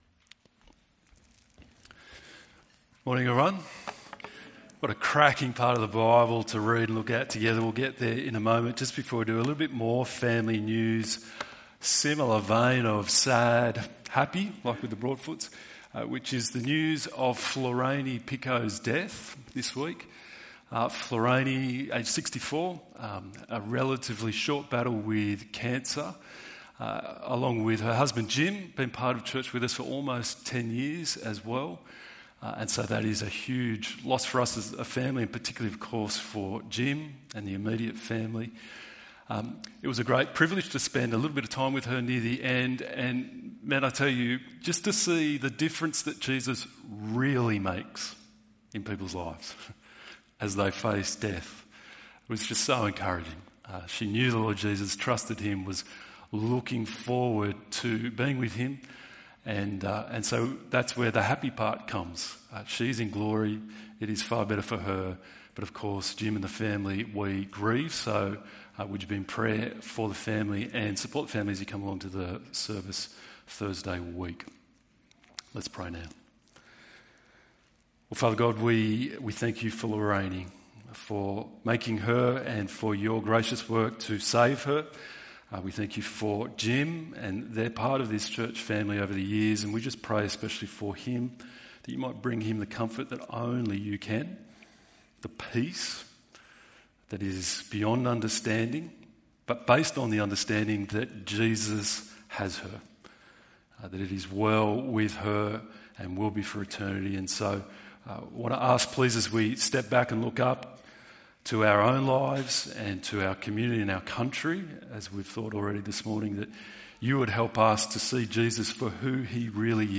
Jesus' Agenda ~ EV Church Sermons Podcast